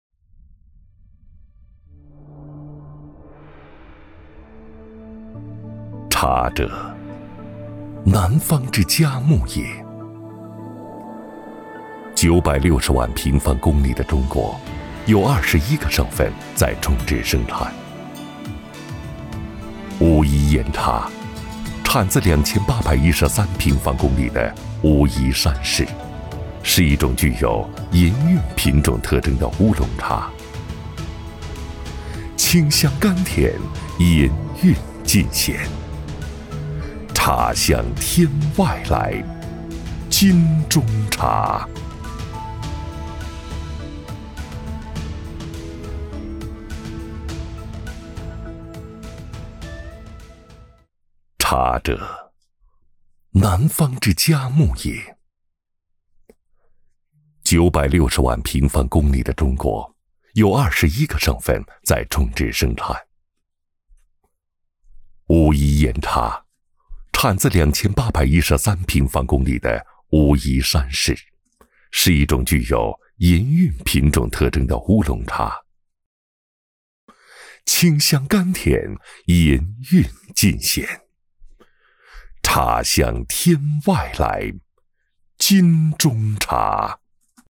广告-男29-文雅-茶香天外来——金中茶.mp3